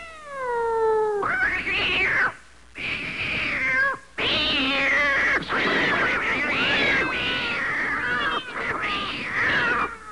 Big Catfight Sound Effect
big-catfight.mp3